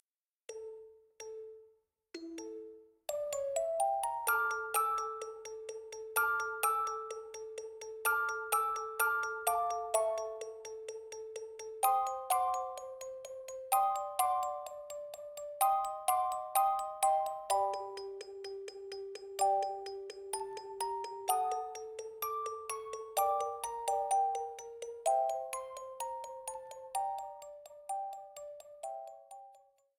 Self-ripped
shortened to 30 seconds, added fadeout